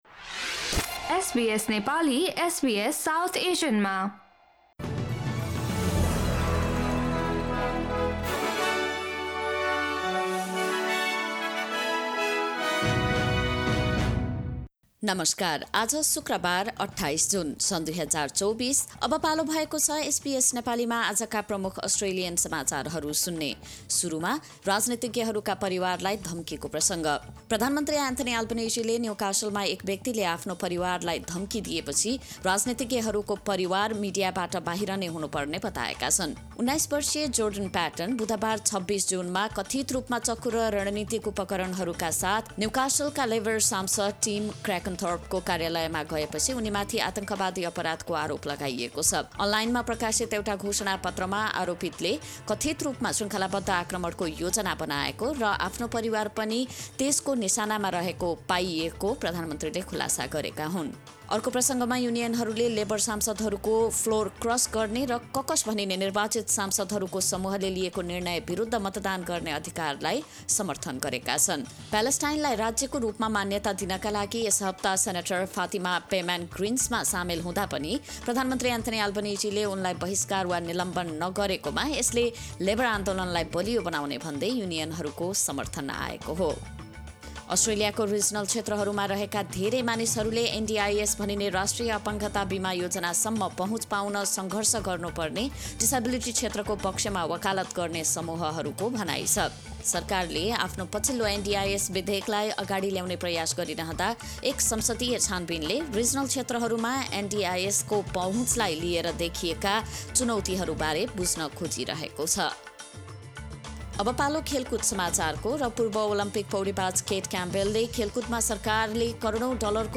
SBS Nepali Australian News Headlines: Friday, 28 June 2024